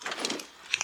PixelPerfectionCE/assets/minecraft/sounds/item/armor/equip_diamond2.ogg at mc116
equip_diamond2.ogg